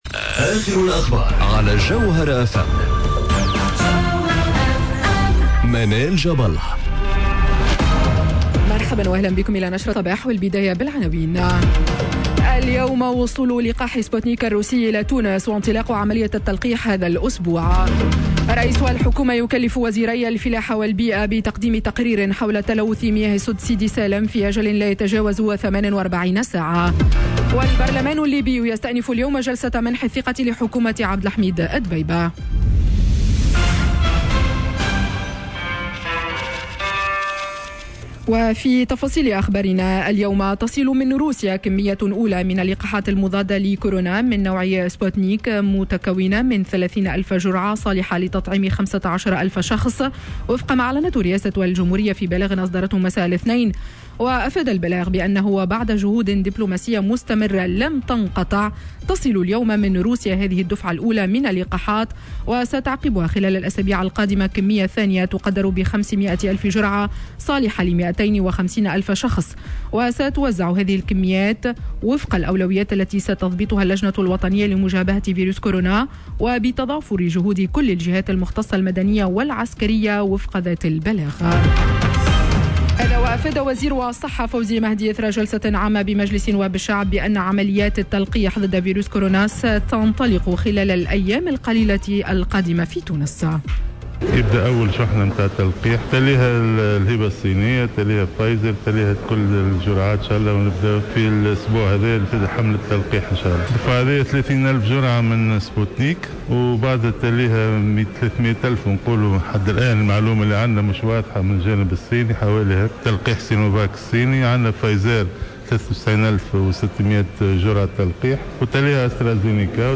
نشرة أخبار السابعة صباحا ليوم الثلاثاء 09 مارس 2021